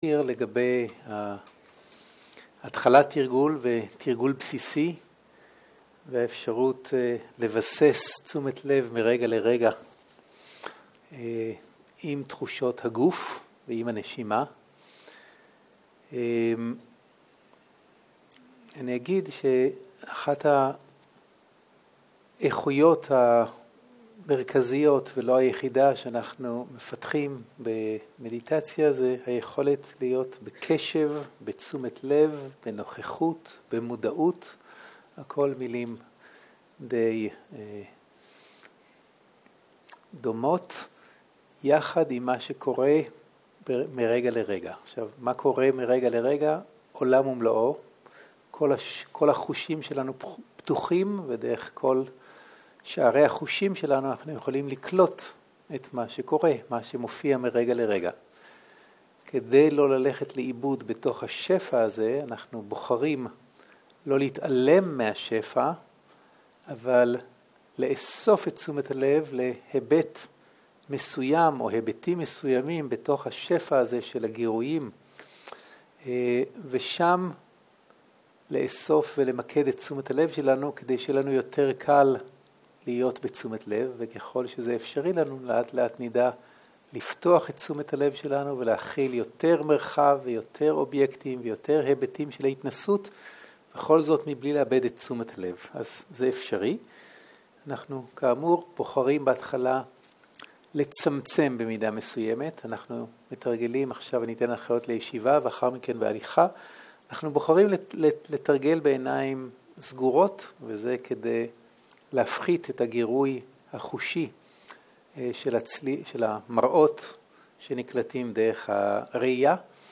ערב - הנחיות מדיטציה
Dharma type: Guided meditation